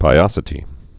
(pī-ŏsĭ-tē)